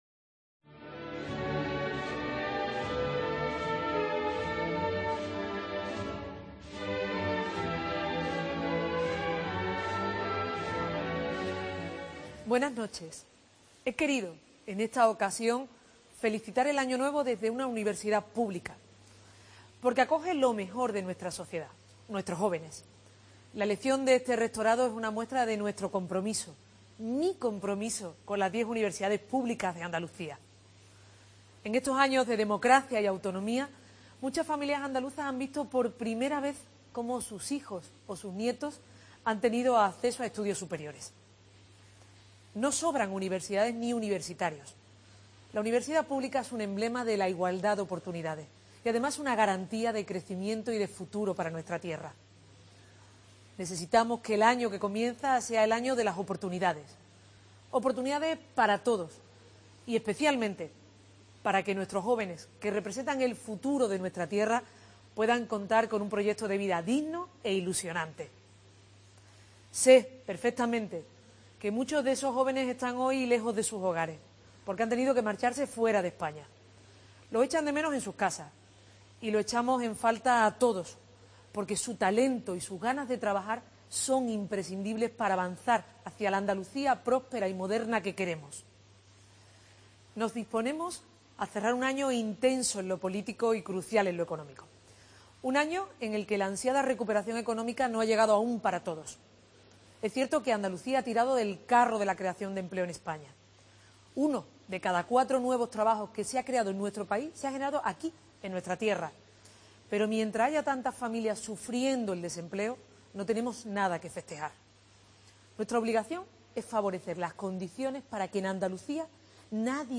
Mensaje de Fin de Año de la presidenta de la Junta de Andalucía, Susana Díaz